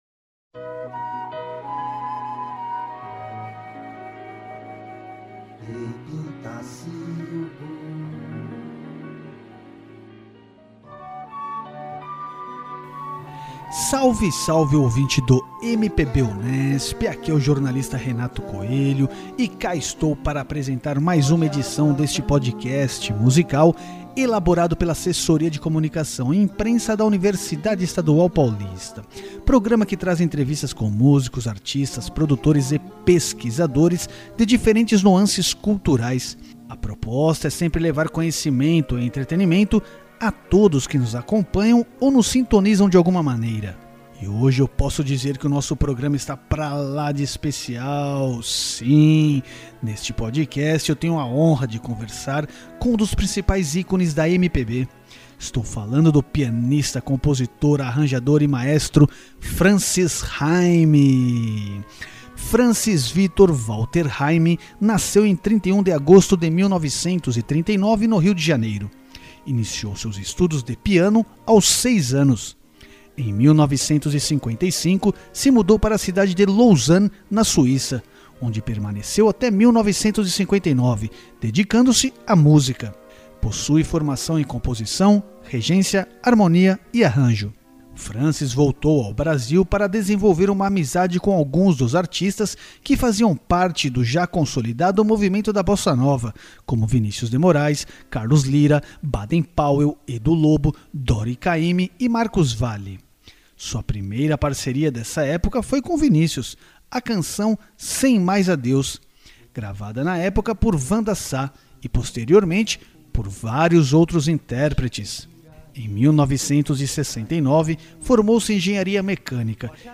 Destaque desta edição do Podcast MPB Unesp, o pianista, compositor, arranjador e maestro, Francis Hime, fala sobre inúmeros pontos da sua trajetória. Desde a relação com o piano na infância até os trabalhos atuais, como o novo álbum "Não Navego pra Chegar", que terá show de lançamento no Rio de Janeiro, no mês de maio.
O PodMPB traz áudios de entrevistas com pesquisadores e músicos de diferentes gêneros, com a proposta de oferecer entretenimento e conhecimento ao ouvinte.